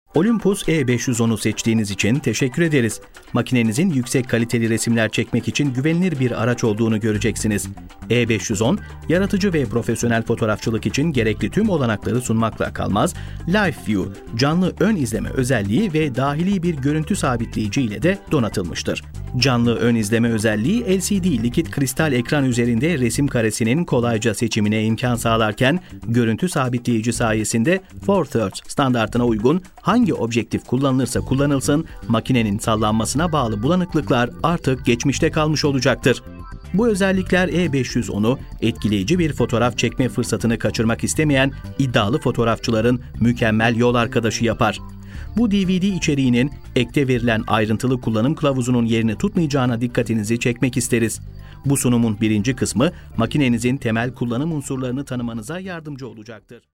Sprecher türkisch.
Sprechprobe: eLearning (Muttersprache):